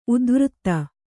♪ udvřtta